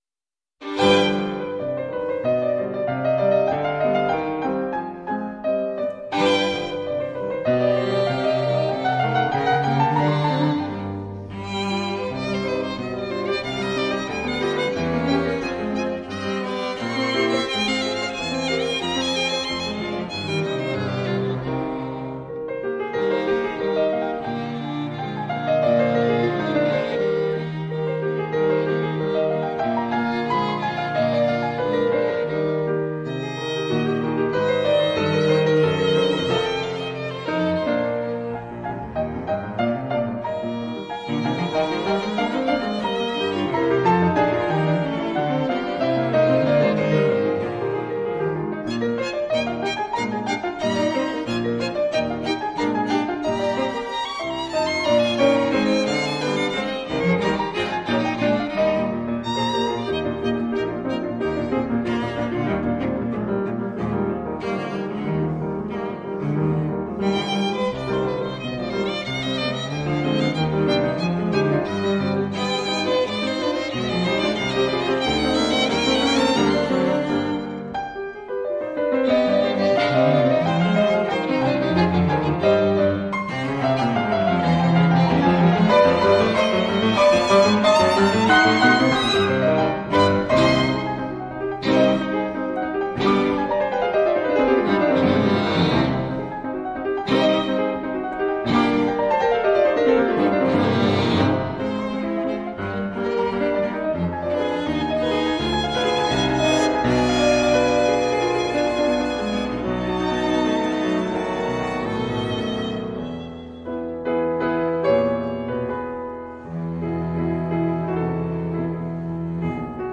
Soundbite 4th Movt